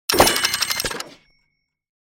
clockStart.mp3